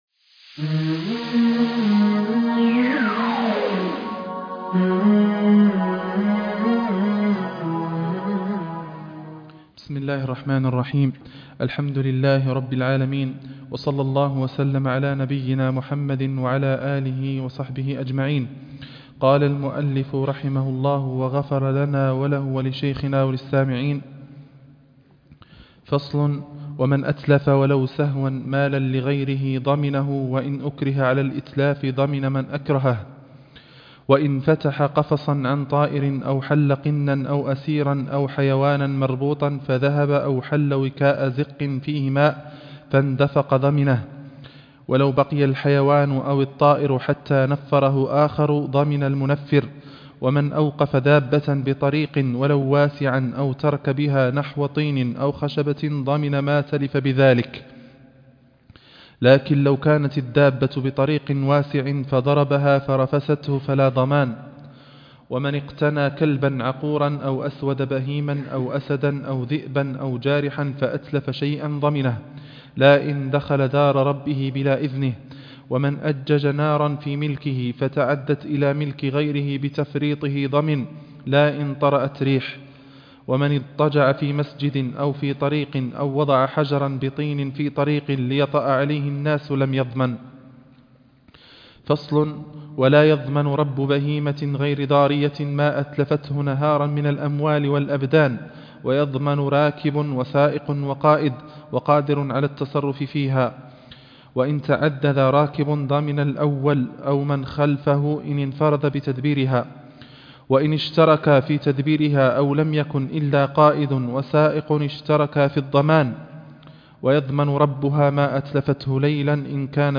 الدرس ( 71) باب الضمان - كتاب دليل الطالب